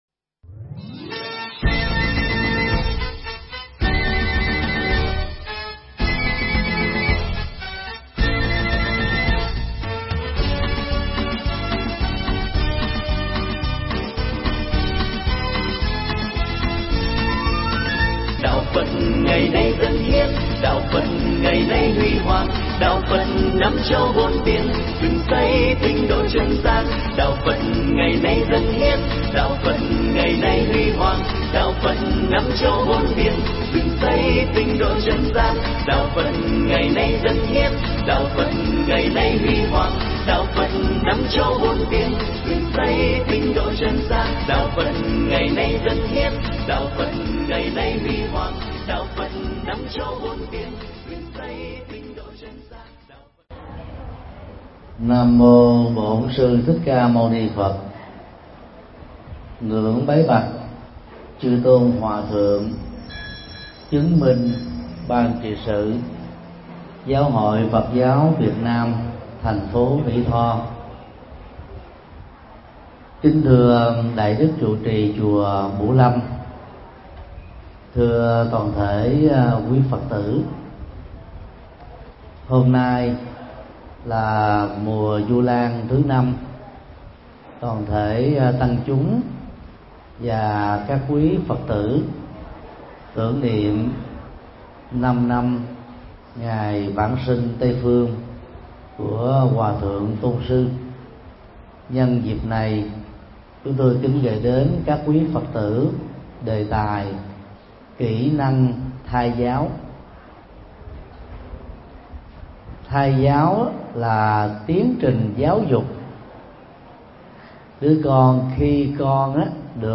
Tải mp3 Bài pháp thoại Kỹ năng thai giáo do TT Thích Nhật Từ Giảng tại chùa Bửu Lâm, ngày 18 tháng 08 năm 2013